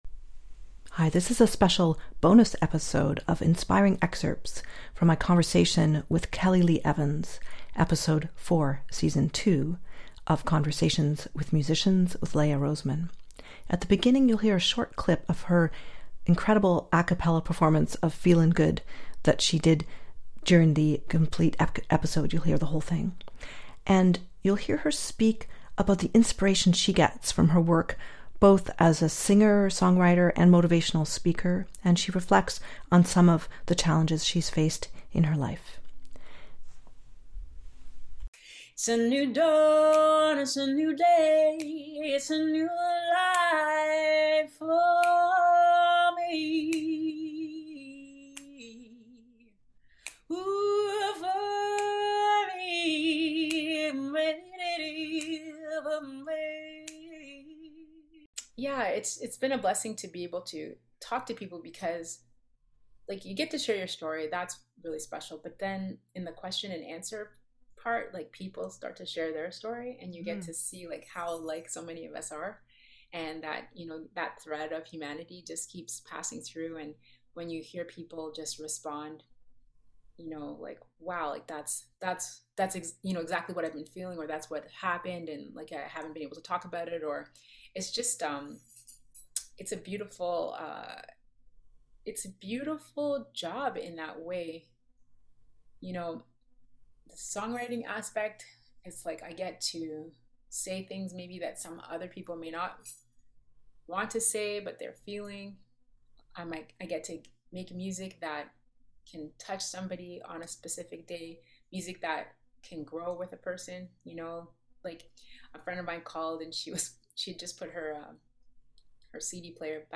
Jazz singer, song-writer and motivational speaker